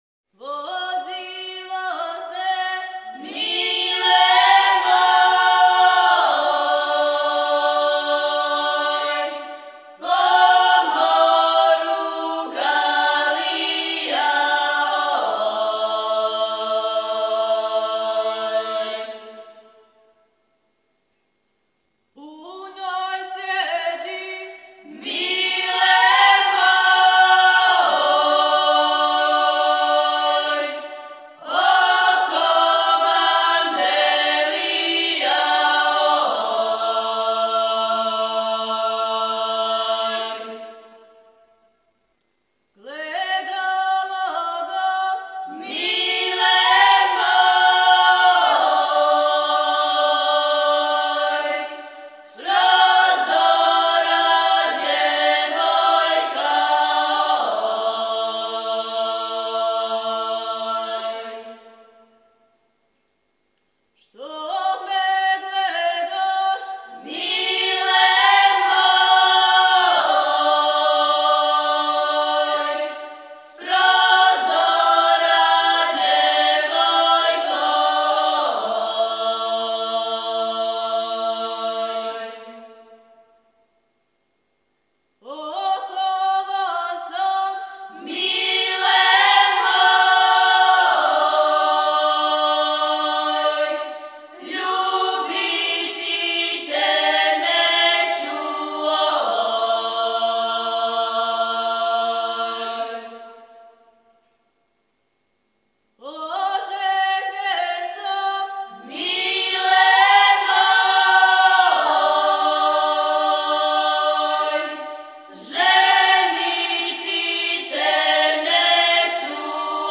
Снимци КУД "Др Младен Стојановић", Младеново - Девојачка и женска певачка група (7.1 MB, mp3) О извођачу Албум Уколико знате стихове ове песме, молимо Вас да нам их пошаљете .